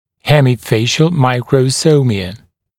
[ˌhemɪ’feɪʃl ˌmaɪkrə’səumɪə][ˌхэми’фэйшл ˌмайкрэ’соумиэ]гемифациальная микросомия